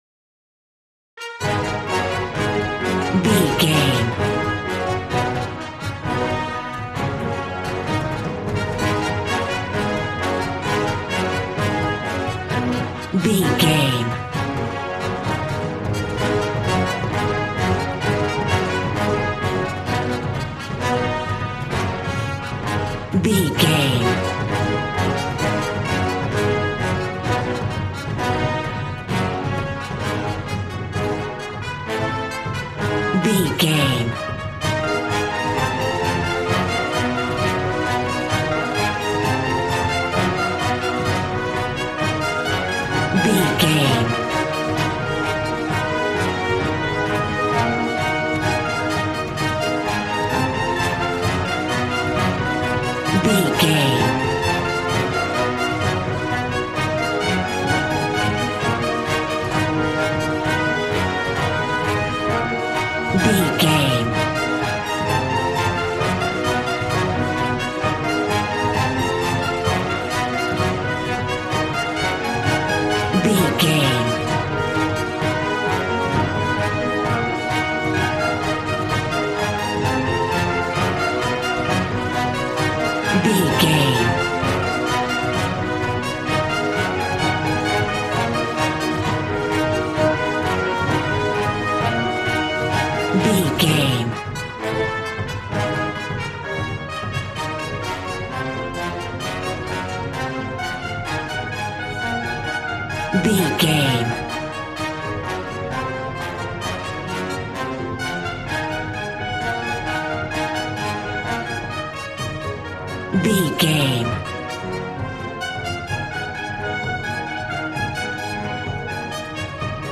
Aeolian/Minor
E♭
brass
strings
violin
regal